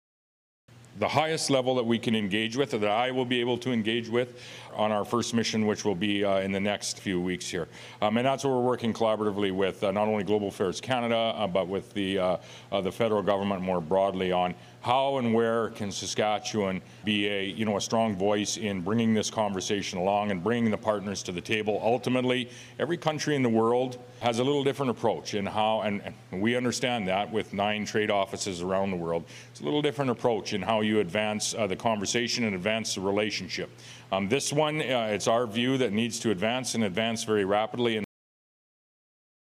Moe indicated additional details about his trip to China will be released in a few days but had this to say at a news conference following Thursday’s meeting.